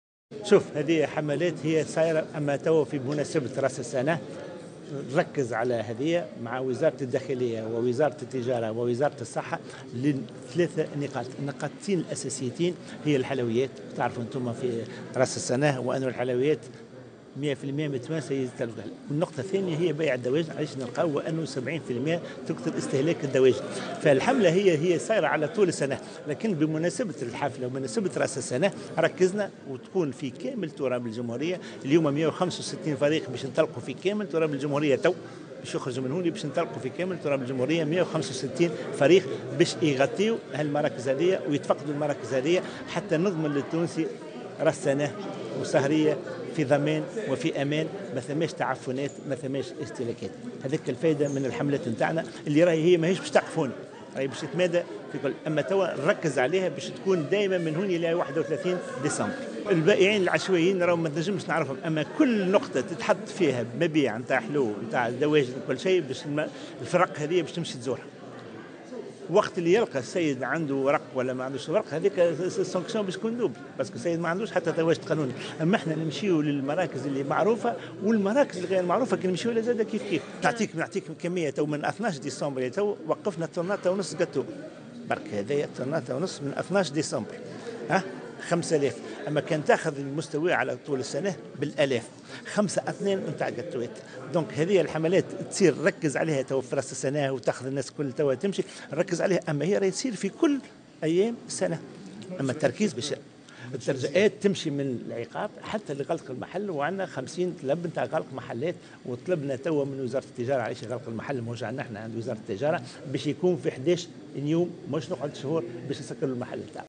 وأضاف في تصريح اليوم لمراسلة "الجوهرة أف أم" أنه تم تخصيص 165 فريق مراقبة على كامل تراب الجمهورية، مشدّدا على أن المخالفات قد تصل إلى حدّ إصدار قرارات بالغلق للمحلات المخالفة.